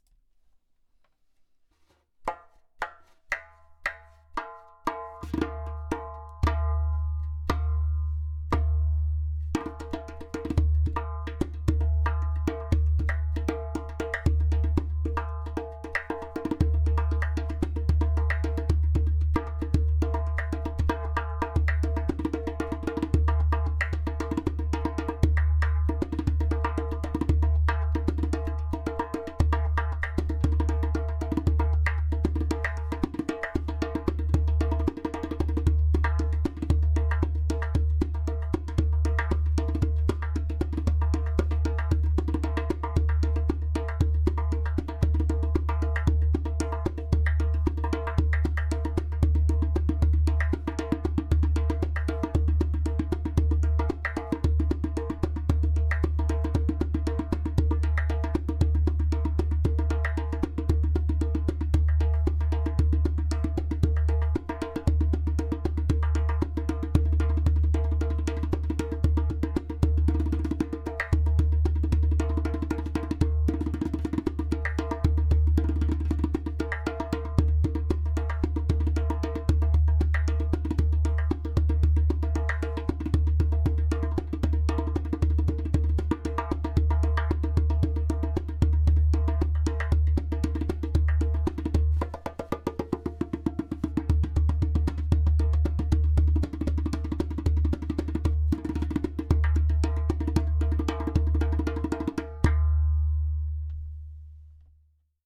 115bpm
130bpm
In this line of darbukas materials like clay, glaze and natural skin met in a magical way which brings into life a balanced harmonic sound.
• Strong and super easy to produce clay kik (click) sound
• Deep bass
• Even tonality around edges.
• Beautiful harmonic overtones.